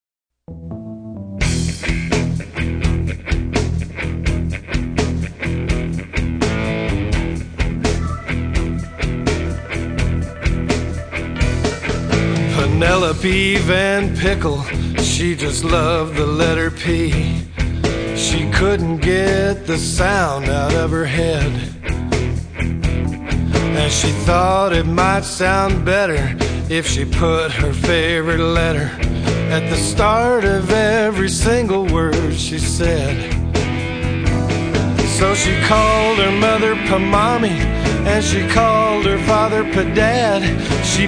Phonics Songs